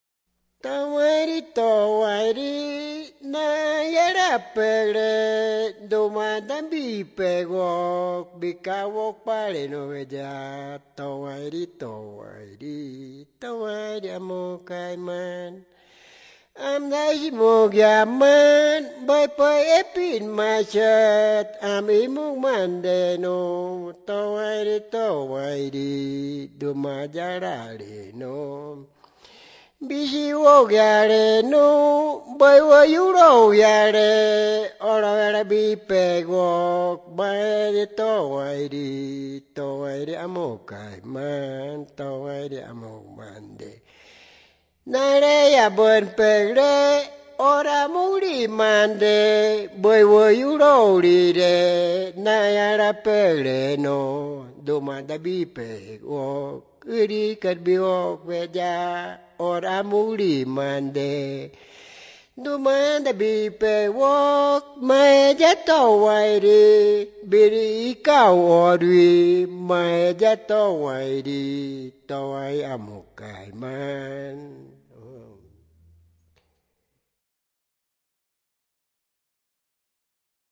Piedra Alta, Medio Inírida, Guainía (Colombia)
Locución al primo cruzado a manera de brindis celebrando la ocasión y deseando que habrá otro momento para volver a tomar esta agua rojiza (primo cruzado, cuñado). La grabación, transcripción y traducción de la canción se hizo entre marzo y abril de 2001 en Piedra Alta; una posterior grabación en estudio se realizó en Bogotá en 2003
Canciones Wãnsöjöt